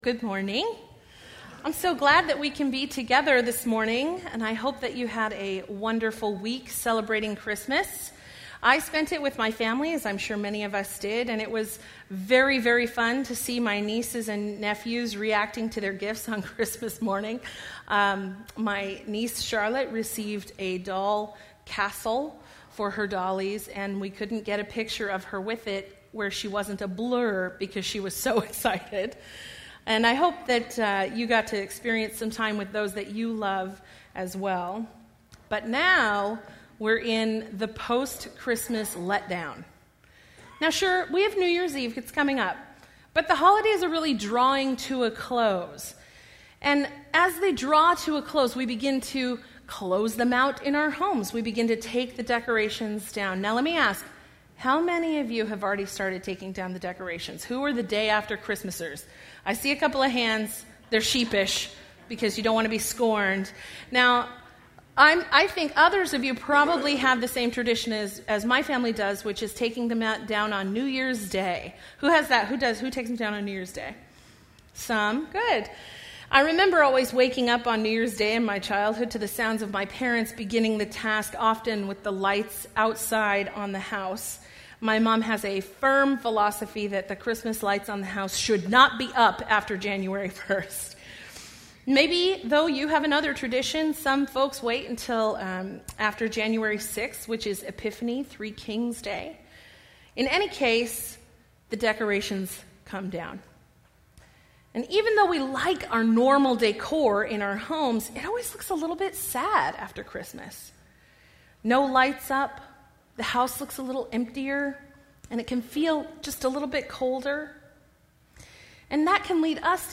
Bible Text: Luke 2:21-52 | Preacher